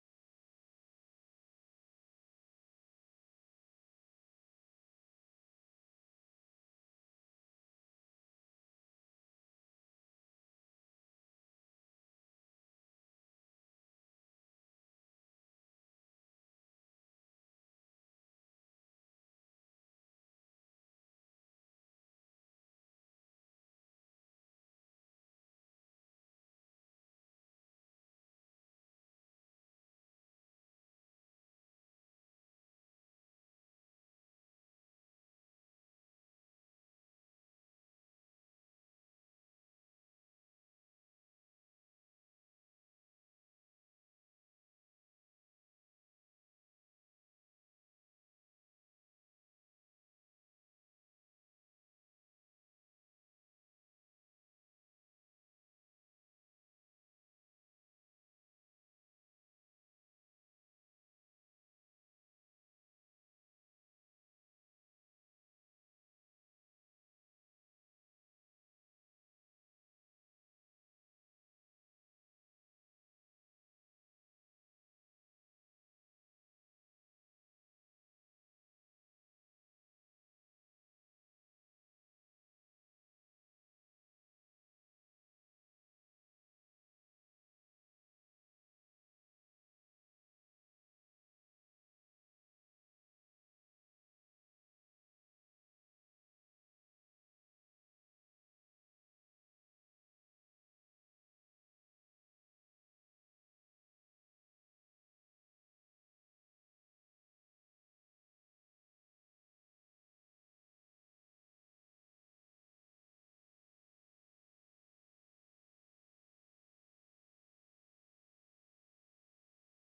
ምርጥና አዳዲስ የካንትሪ ማለትም የአሜሪካ አገር ሰብእ፣ የሮክ፣ተሶል፣የፖፕ፣የአር-ኤን-ቢ፣ብሉዝ፣የሬጌና የሂፕ-ሃፕ ዜማዎችን